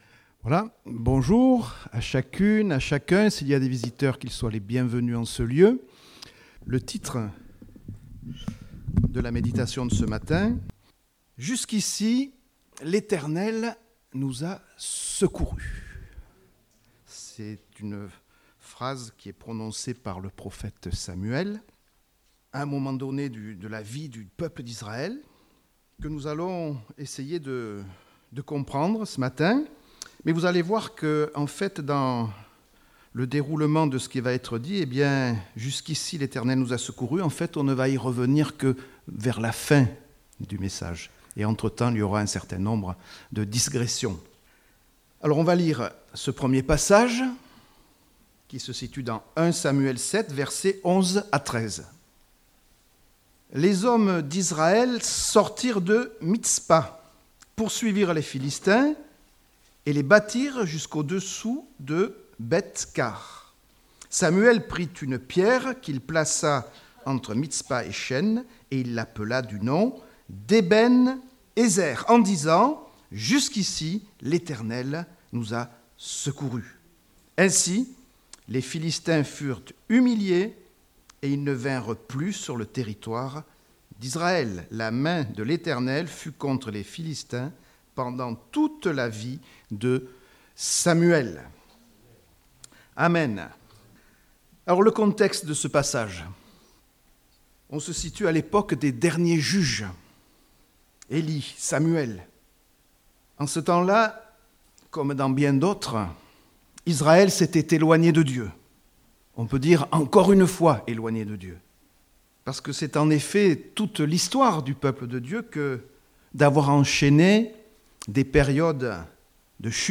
Date : 24 février 2019 (Culte Dominical)